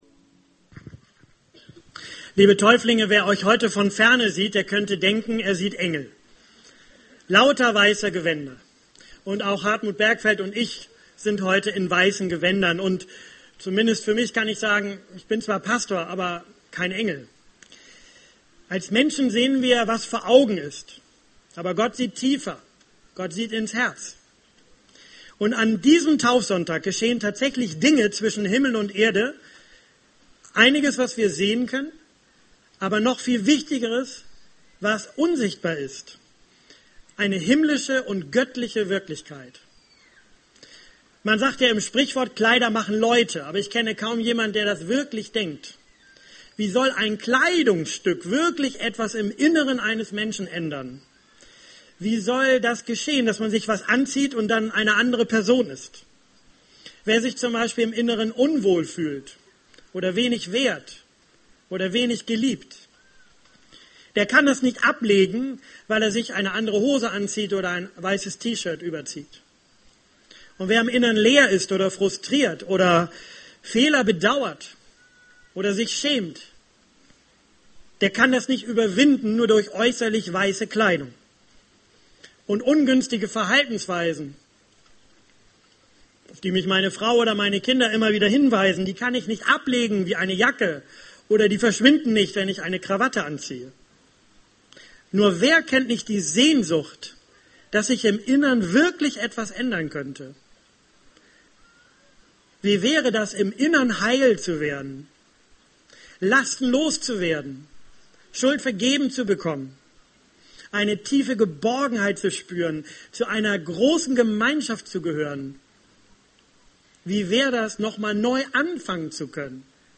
Taufpredigt – Jesus Christus vertrauen – sich fallen lassen und himmlisch eingekleidet werden